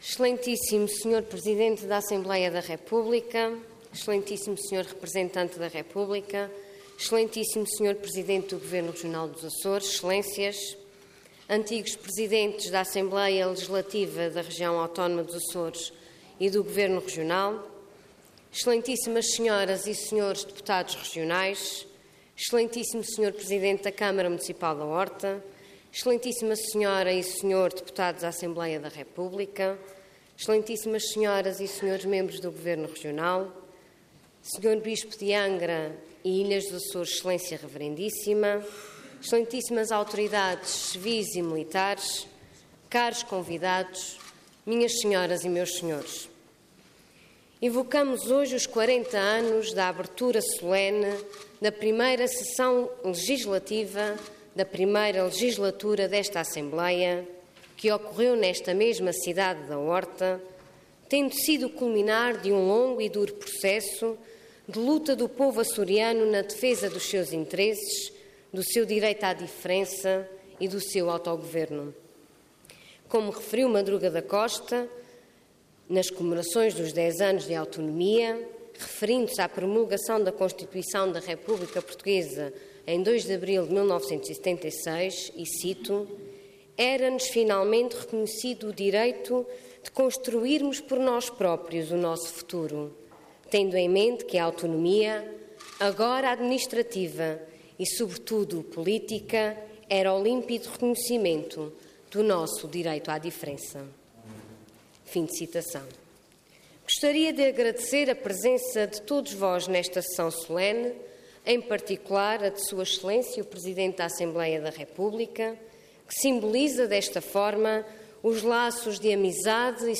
Parlamento online - Sessão Solene Evocativa dos 40 anos da Autonomia dos Açores
Detalhe de vídeo 4 de setembro de 2016 Download áudio Download vídeo X Legislatura Sessão Solene Evocativa dos 40 anos da Autonomia dos Açores Intervenção Orador Ana Luísa Luís Cargo Presidente da Assembleia Regional Entidade ALRAA